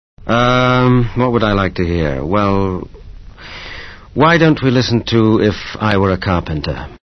Interview David!